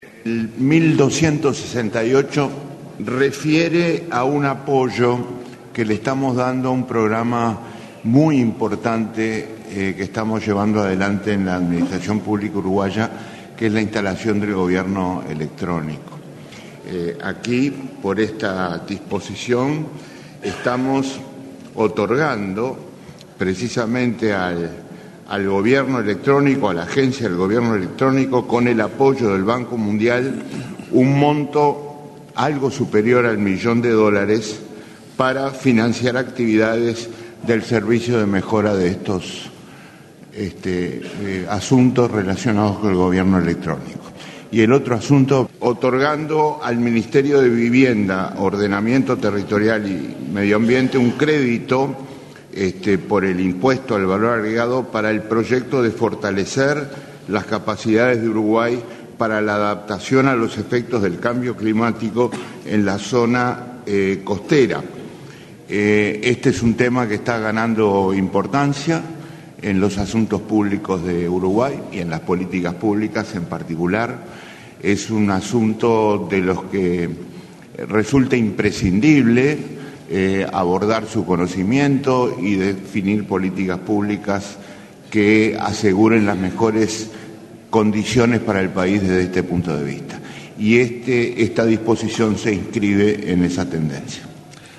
El Ministerio de Economía y Finanzas otorgó US$ 1millón para el desarrollo del programa de Gobierno Electrónico. El ministro Danilo Astori destacó, en el Consejo de Ministros abierto en La Teja, que esta es “una iniciativa muy importante que lleva adelante la administración pública uruguaya”.